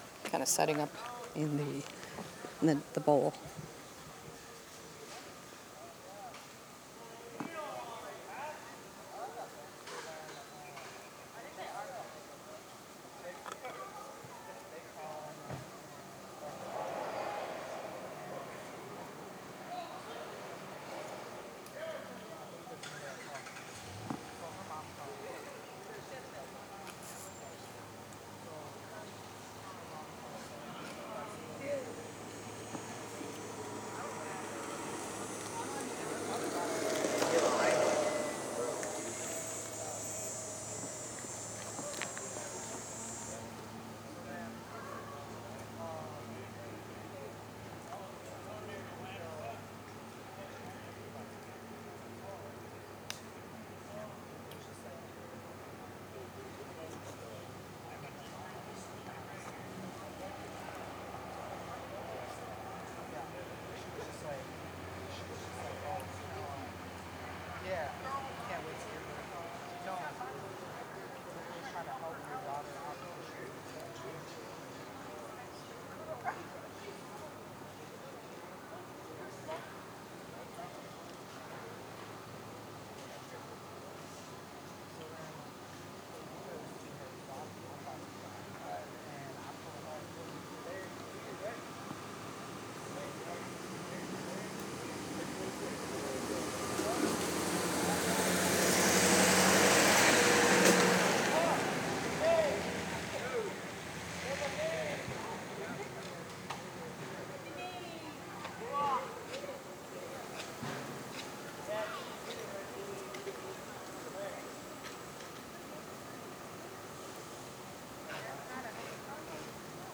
Menominee PowWow 4 Aug 2023 Set Up in Bowl.wav